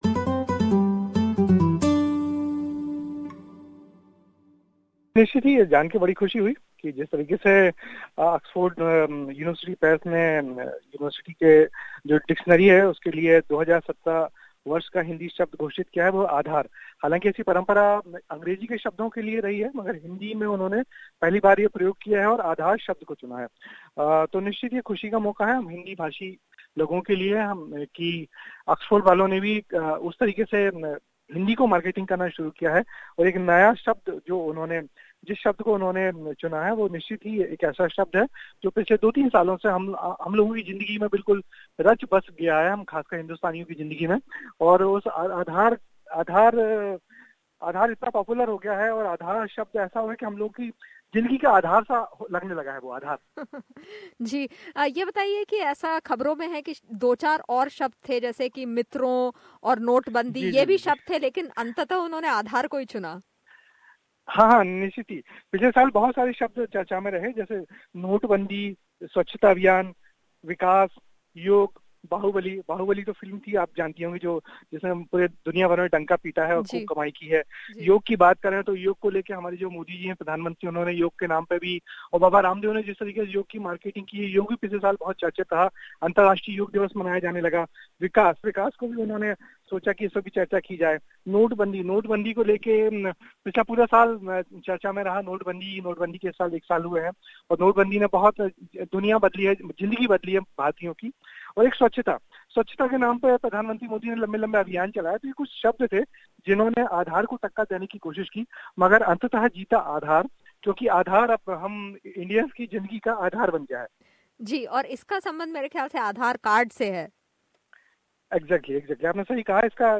Oxford Dictionary has declared its first Hindi word of the year. We spoke to eminent journalist and Hindi author